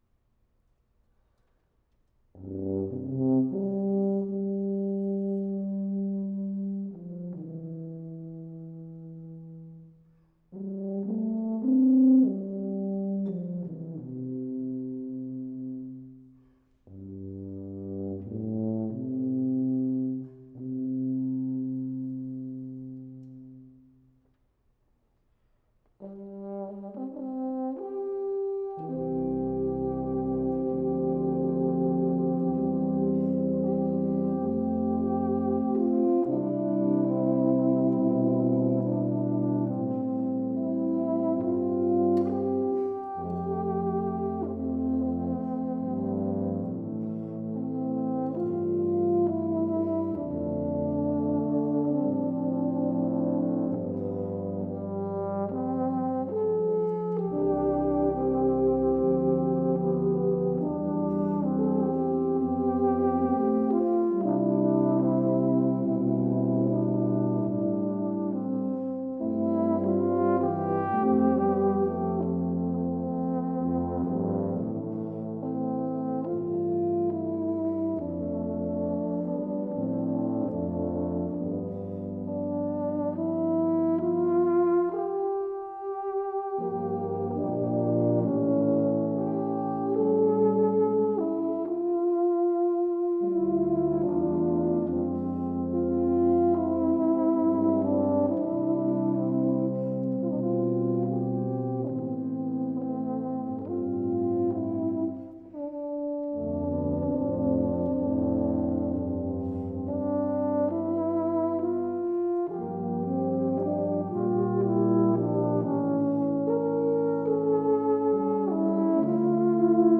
Sample #1: Tuba and Euphonium Ensemble (03:25) (27MB/file).
B-format files for the experimental and Soundfield MkV mics.